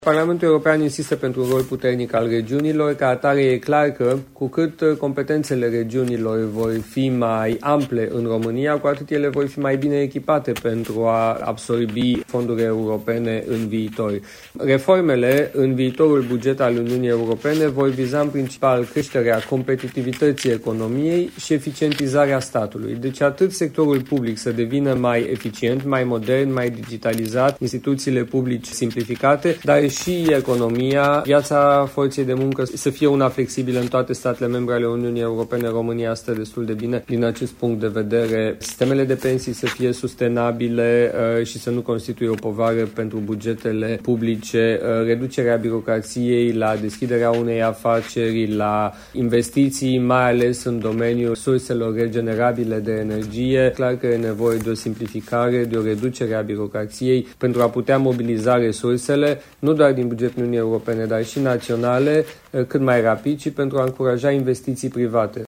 În cadrul unei întâlniri cu jurnaliști la Strasbourg, eurodeputatul Siegfried Mureșan a enumerat mai multe domenii ce ar putea fi vizate de solicitări de reformă la nivel național de către executivul comunitar. Acestea ar putea ține de domeniul public și birocrația care îl caracterizează, dar și de măsuri de creștere a competitivității.